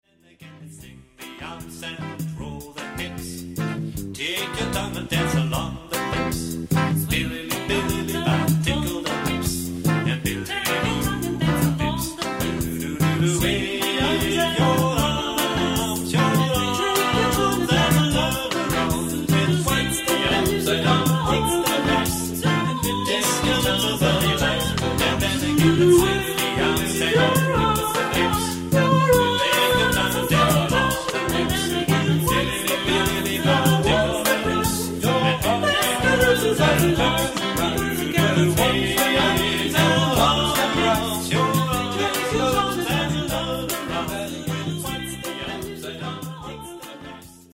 Género/Estilo/Forma: Canon ; Calypso ; Profano
Tipo de formación coral:  (4 voces iguales )
Tonalidad : do mayor